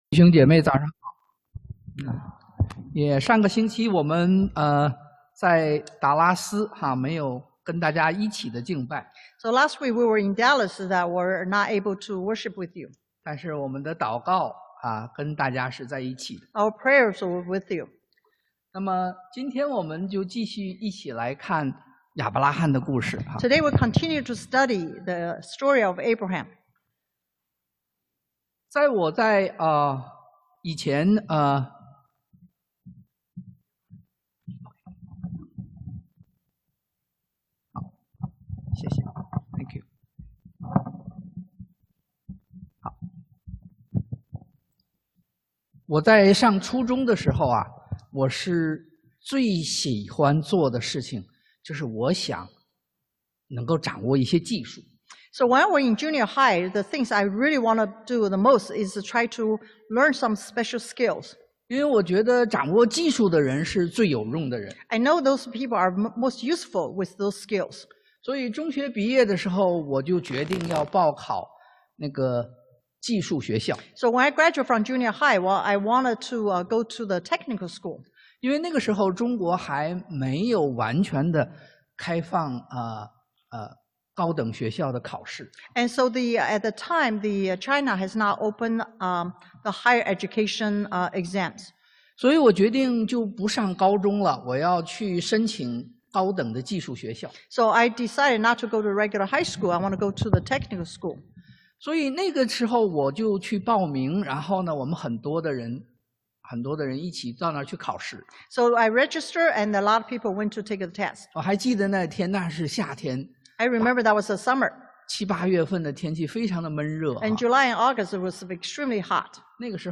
創 Gen 17:1-27 Service Type: Sunday AM 生命轉折點 A Life-Changing Moment 經文Passage